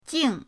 jing4.mp3